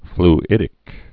(fl-ĭdĭk)